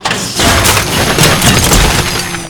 recycle.ogg